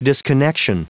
Prononciation du mot disconnection en anglais (fichier audio)
Prononciation du mot : disconnection